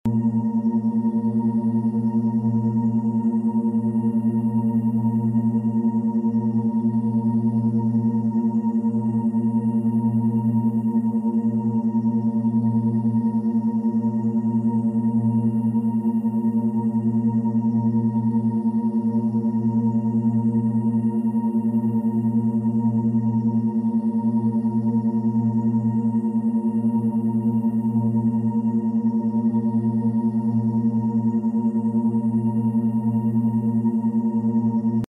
Serotonin Release with Alpha Waves sound effects free download
Serotonin Release with Alpha Waves - 10 Hz Bineural Beats